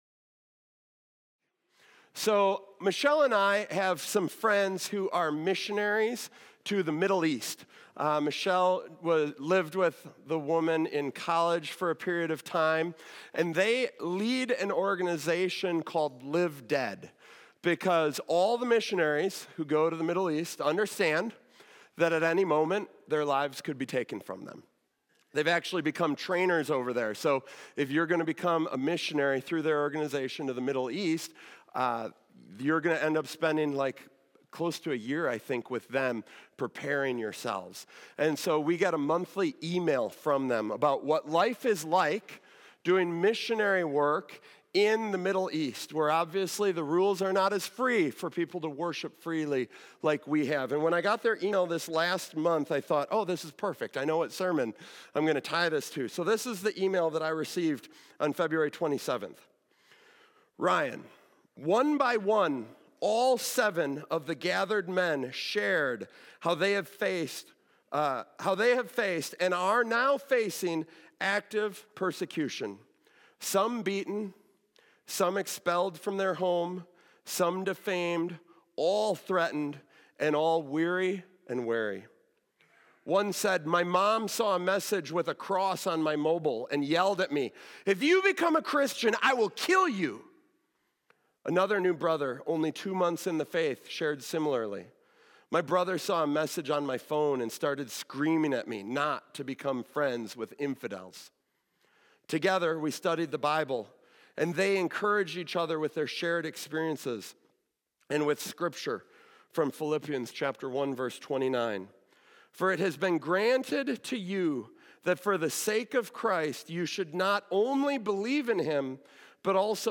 In this message, we explore how Jesus describes the world’s hate toward His followers, focusing on John 15:18–16:4. He warns that those who follow Him will face persecution, just as He did.